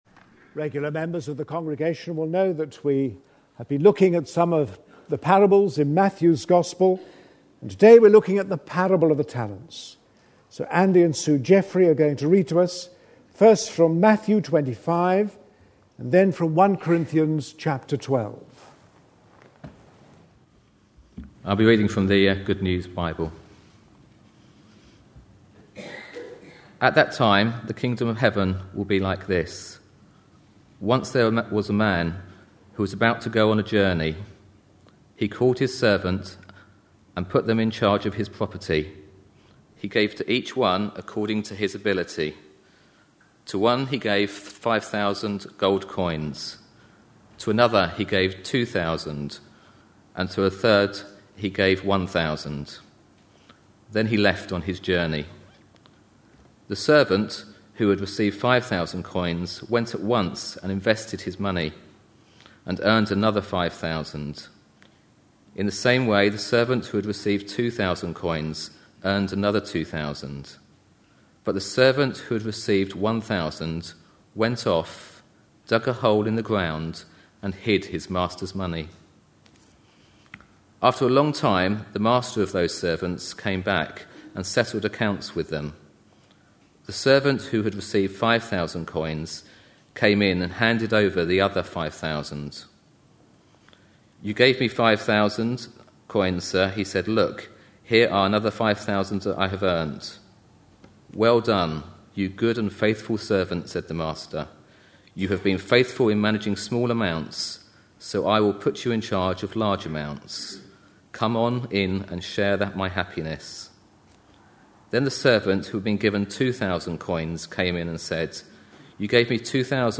A sermon preached on 7th November, 2010, as part of our Parables of Matthew series.